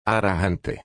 I can’t figure out how to shorten the ending “e”, however.